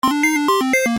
جلوه های صوتی
دانلود صدای بمب 4 از ساعد نیوز با لینک مستقیم و کیفیت بالا